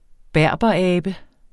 berberabe substantiv, fælleskøn Bøjning -n, -r, -rne Udtale [ ˈbæɐ̯ˀbʌˌæːbə ] Oprindelse første led fra Berberiet (dvs.